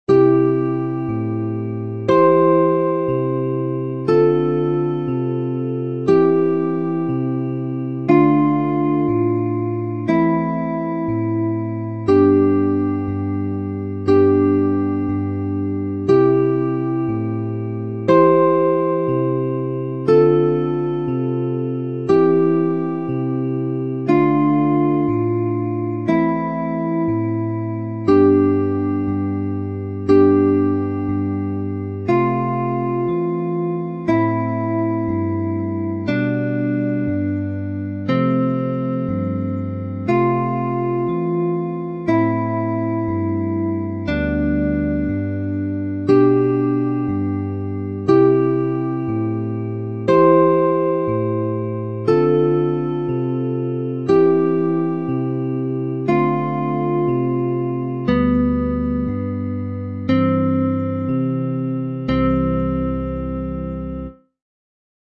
• Жанр: Детская
Музыкальная пьеса-портрет. Корова. Электронное фортепиано.